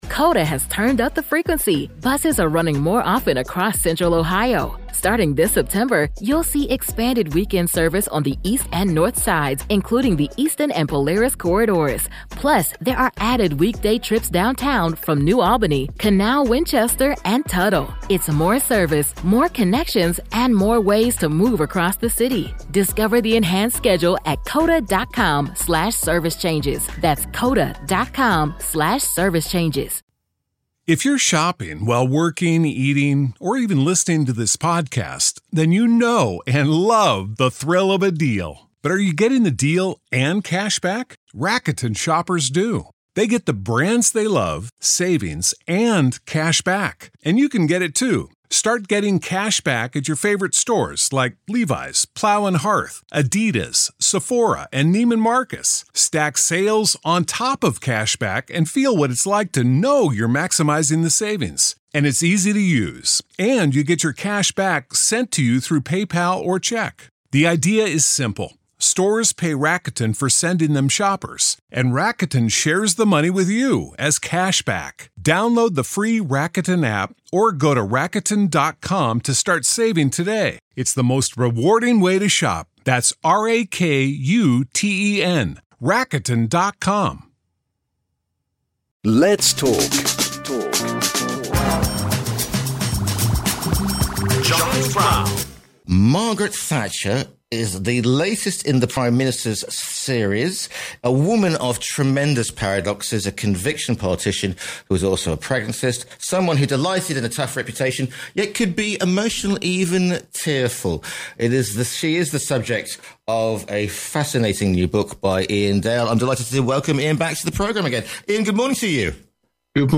Broadcaster and author, Iain Dale, discusses his latest book 'Margaret Thatcher'